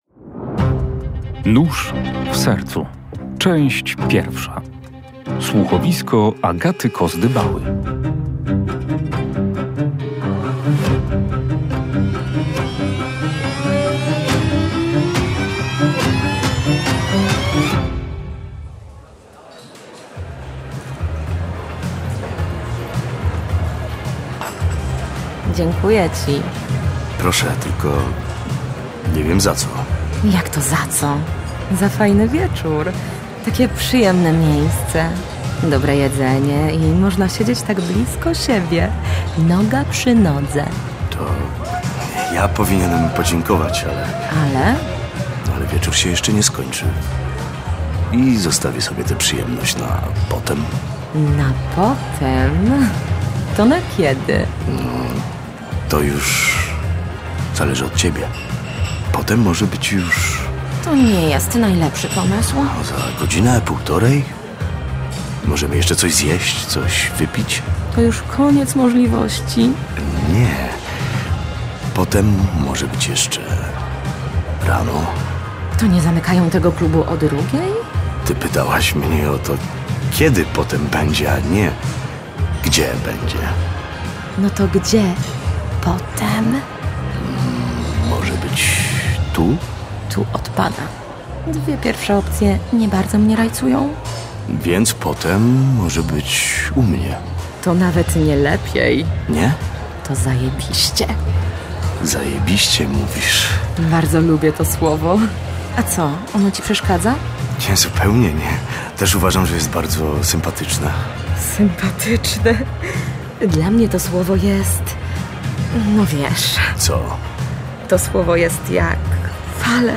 Słuchowisko przeznaczone jest dla osób dorosłych.